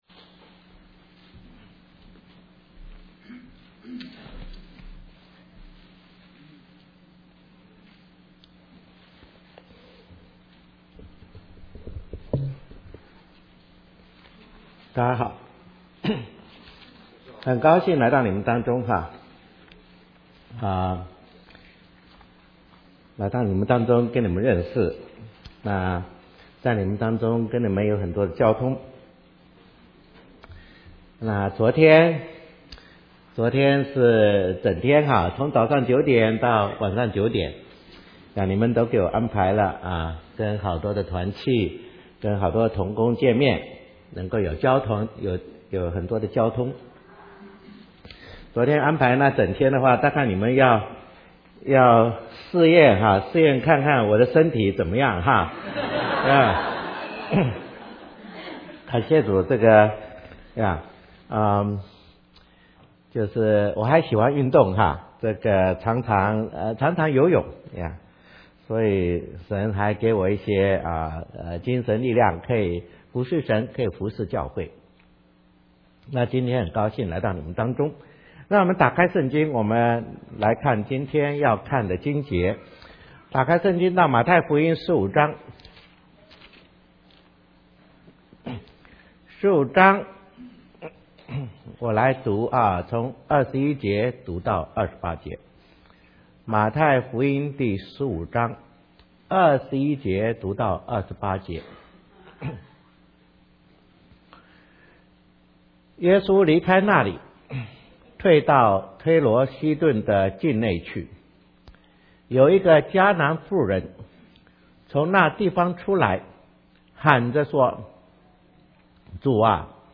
中文讲道